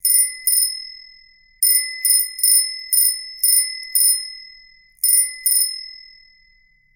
チリンチリン・・！。
自転車のベルの音って割といい音します。